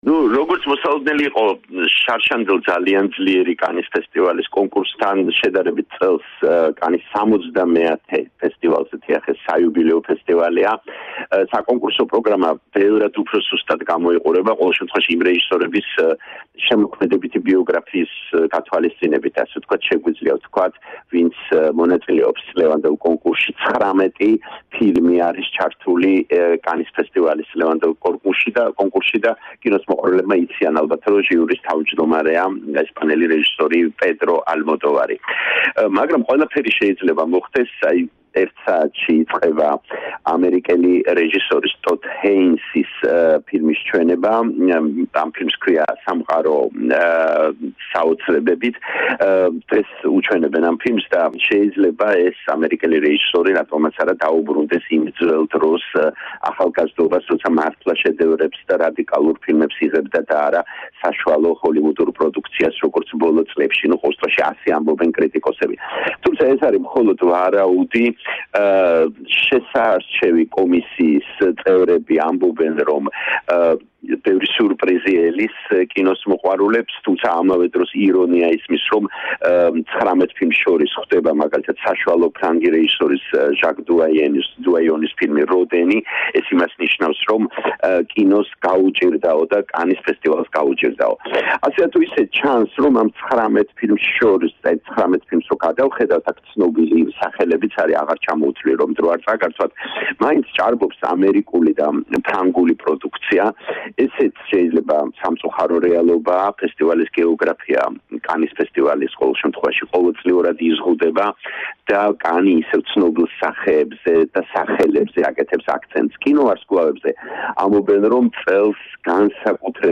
რეპორტაჟი კანის კინოფესტივალიდან